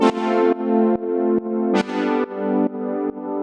GnS_Pad-dbx1:4_140-A.wav